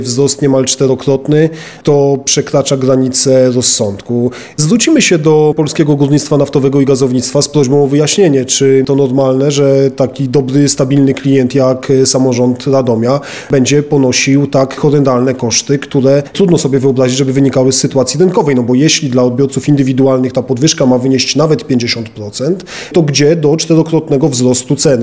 Komentuje wiceprezydent Radomia, Mateusz Tyczyński: